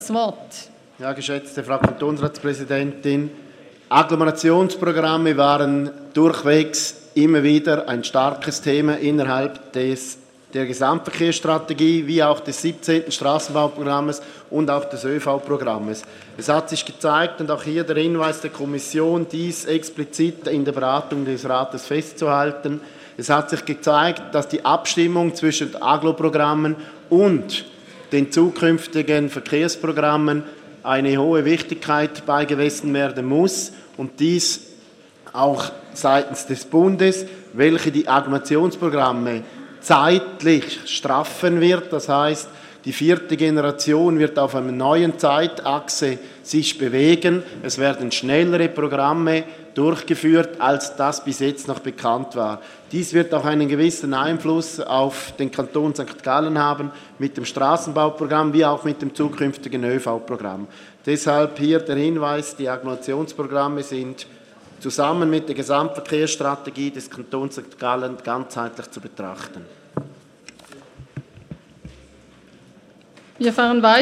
18.9.2018Wortmeldung
Kommissionspräsident, zu 1.6.
Session des Kantonsrates vom 17. bis 19. September 2018